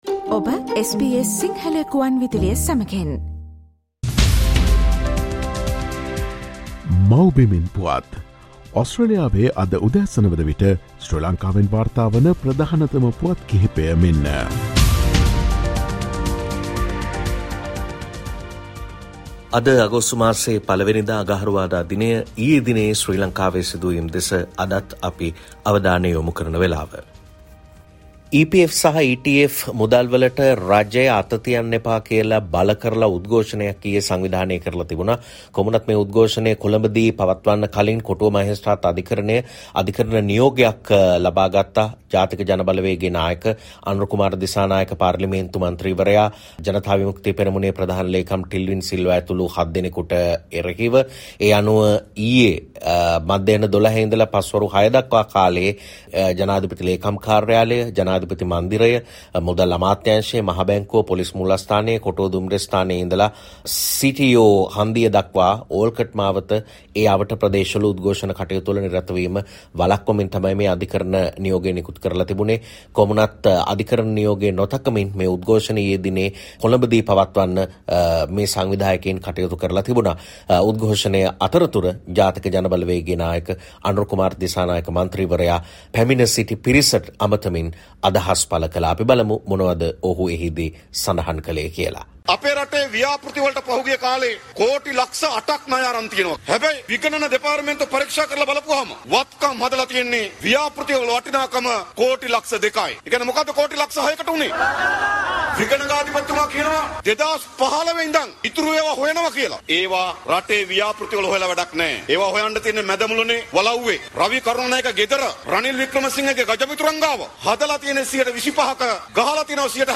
Featuring the latest news reported from Sri Lanka - "Mawbimen Puwath"